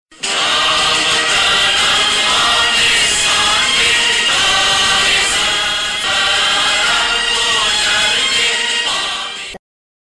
د جرمني په برلین کې ثبت شو.